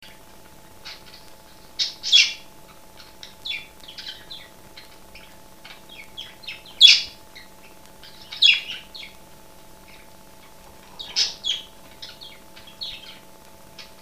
Budgie Birds - Vocals
[n.b. The Budgies calls had to be increased in volume,
thus increasing background computor hum.]
The Four Budgies Vocalising - 11 November 2007 [2].